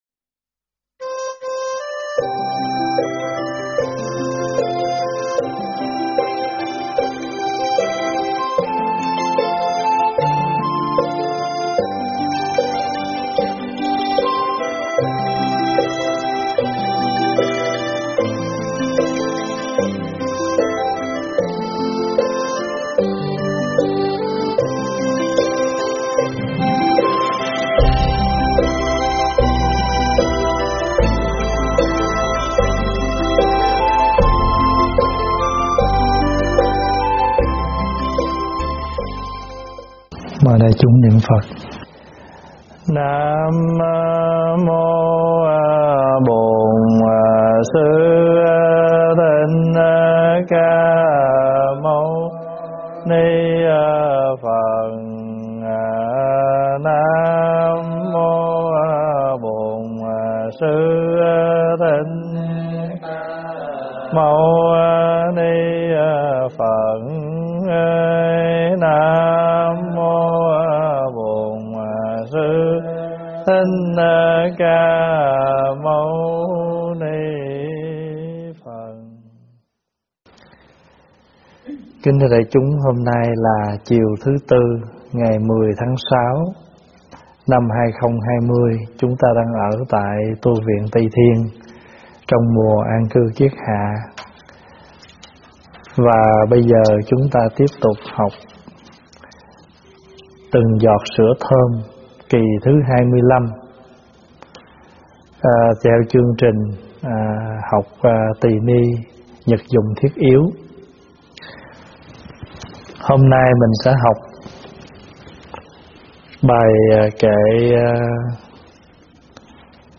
giảng tại Tv Trúc Lâm